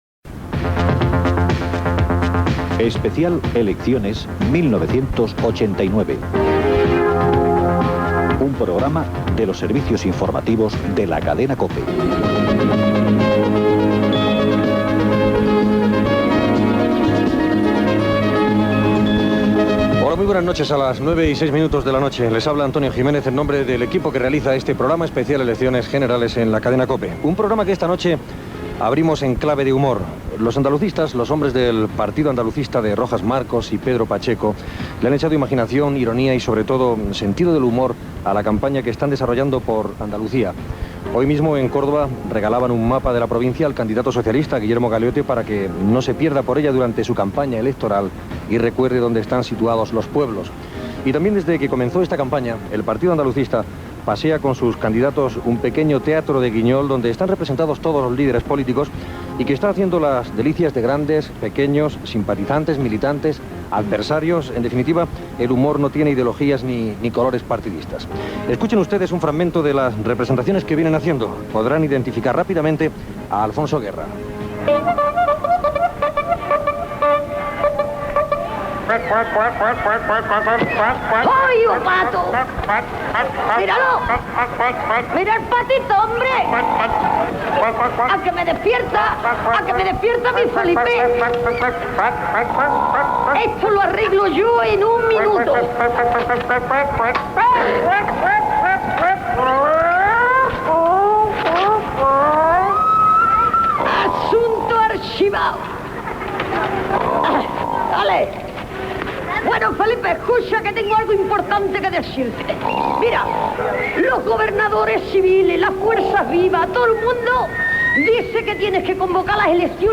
Careta del programa, curiositats sobre la campanya electoral del Partido Andalucista i el seu treatrí de titelles que parodia als polítics del PSOE Alfonso Guerra i Felipe González
Informatiu